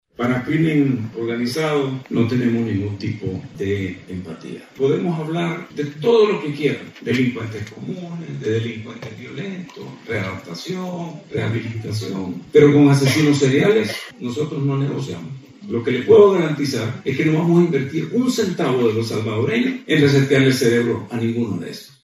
El ministro de Justicia y Seguridad Pública de El Salvador, Gustavo Villatoro, expuso durante un seminario sobre políticas penitenciarias organizado por la Universidad San Sebastián la estrategia de seguridad impulsada por el gobierno del presidente Nayib Bukele.